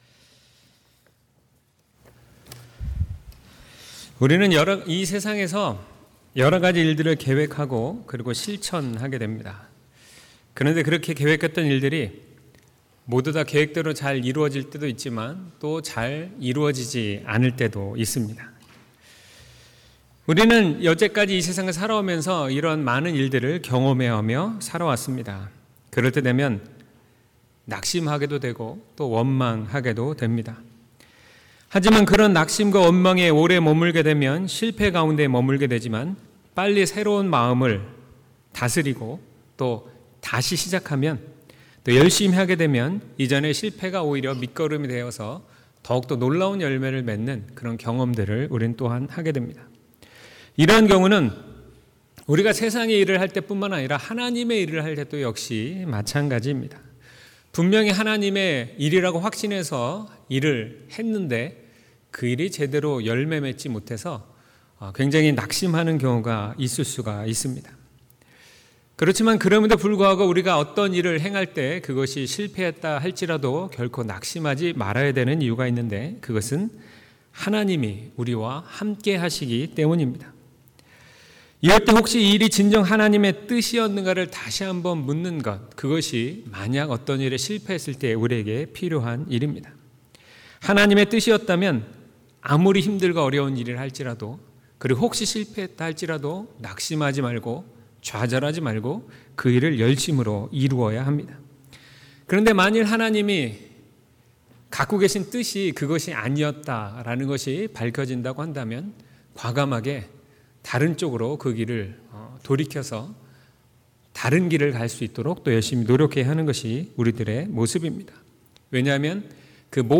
2019년 5월 19일 주일 설교 / 주님의 선교 / 행16:6-15